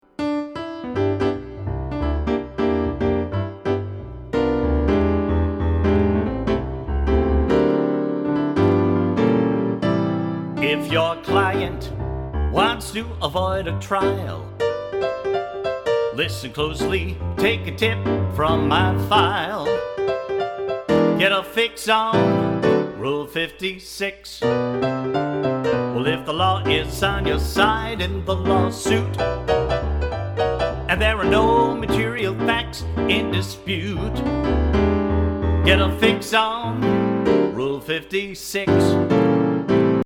musical parodies